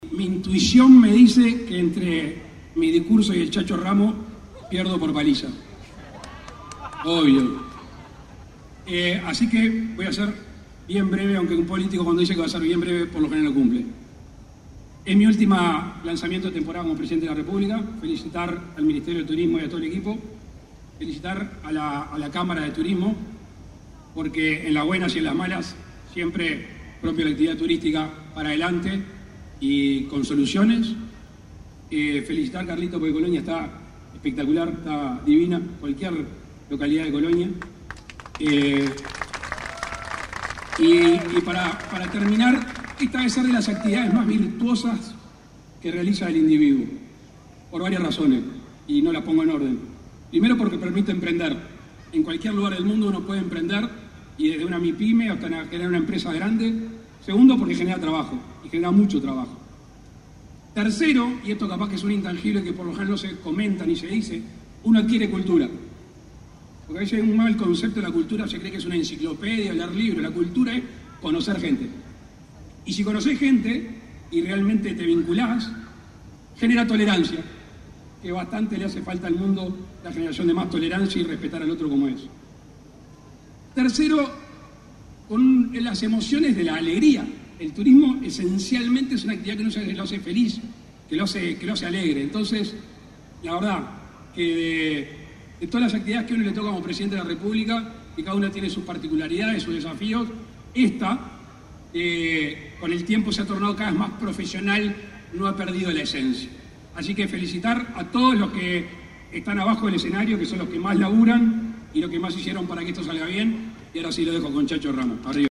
Afuera de la Plaza, se montó un escenario en donde, ante el público presente, hablaron: Carlos Moreira, Eduardo Sanguinetti y Lacalle Pou.